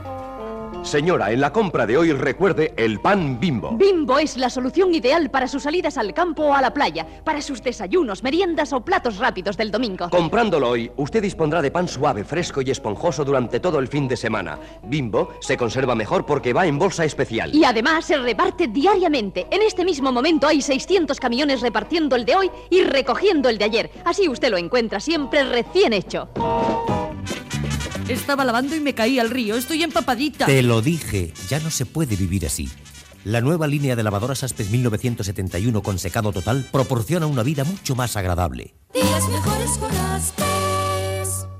Publicitat Anunciant Bimbo, Lavadora Aspes